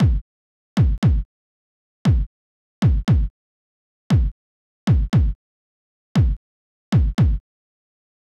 11 Kick.wav